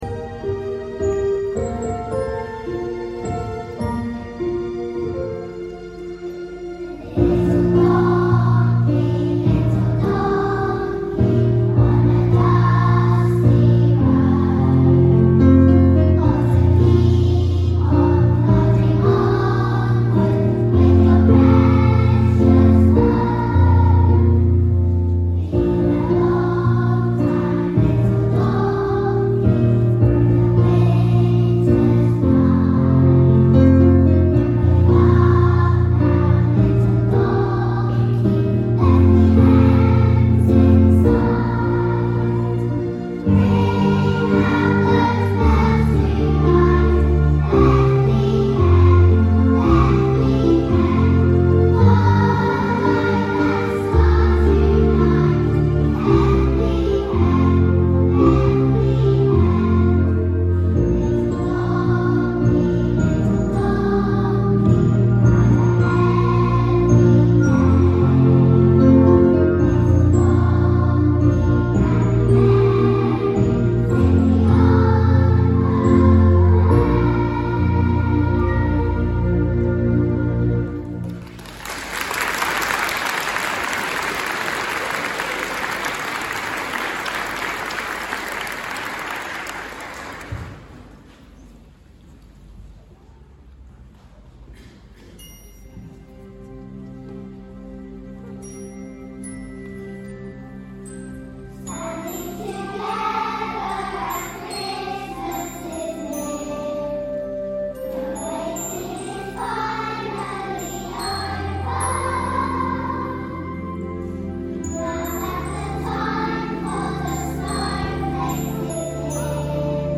Together at Christmas Time | Y2 & 3 Choir